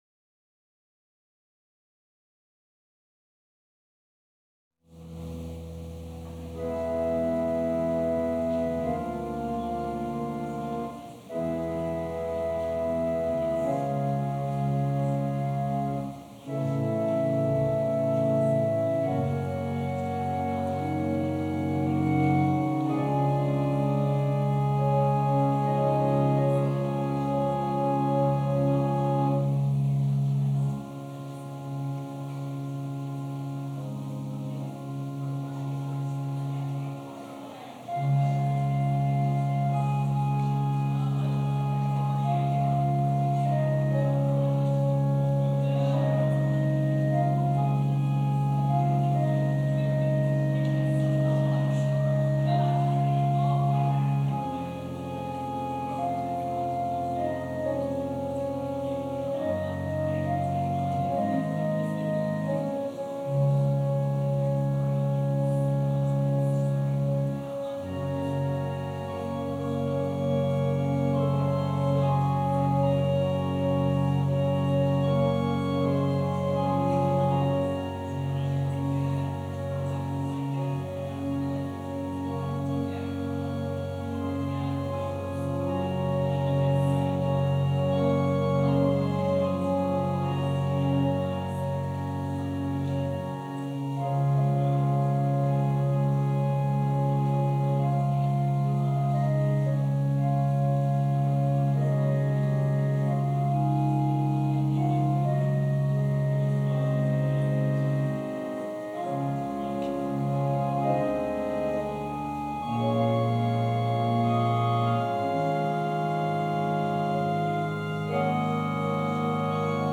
Passage: Luke 9: 28-36 Service Type: Special Service